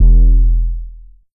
BRIXTON BASS.wav